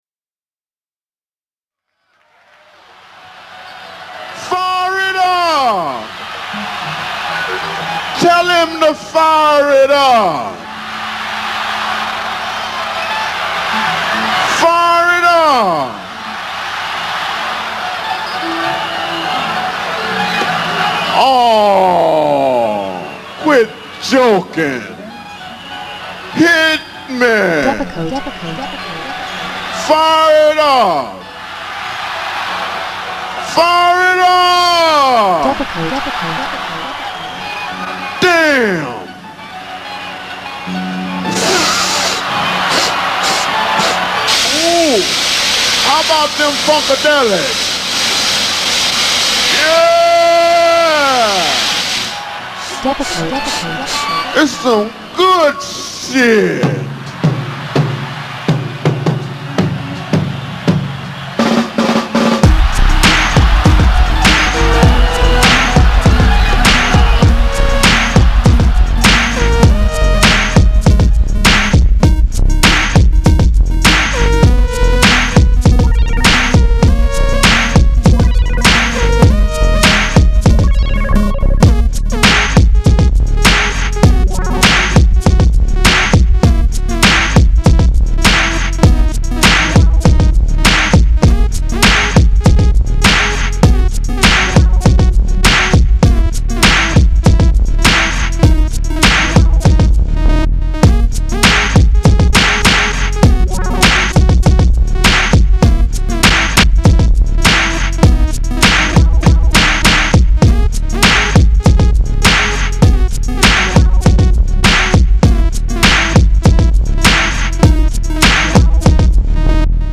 A Mix